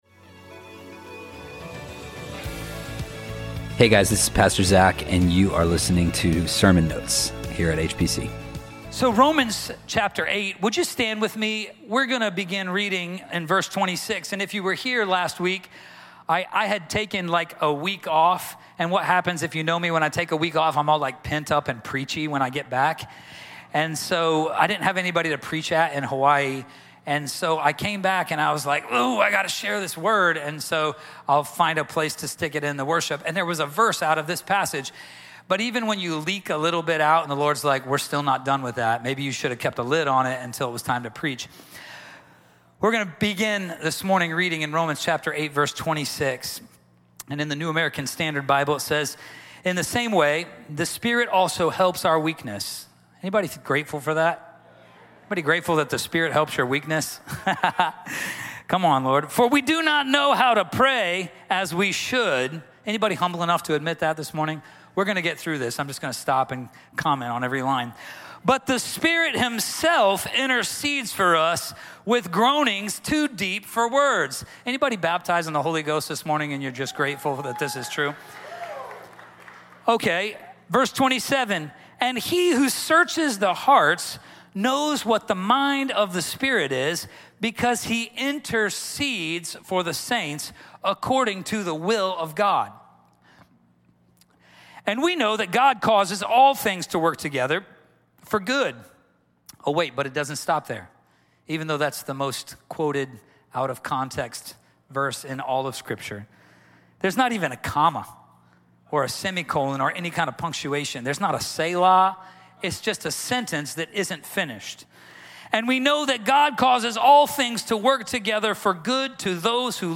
HPC Sermon Notes Podcast - Predestined | Free Listening on Podbean App